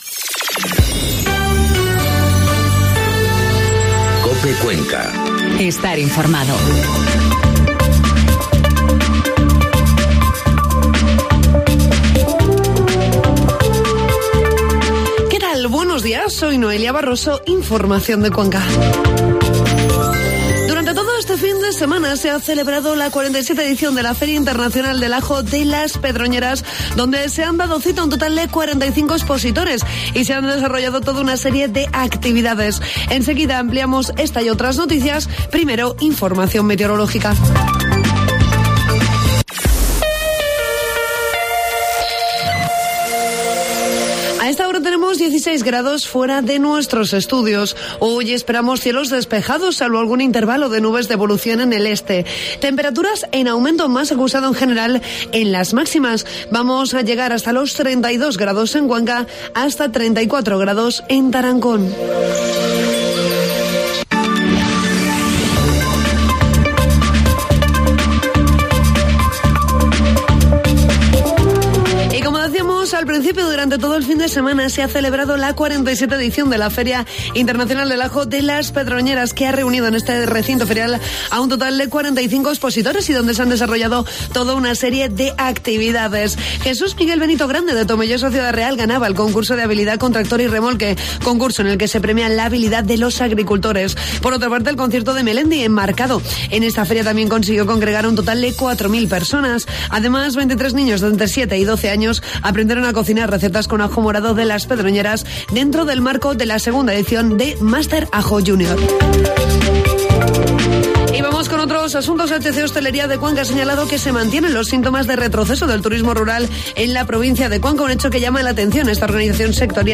Informativo matinal COPE Cuenca 29 de julio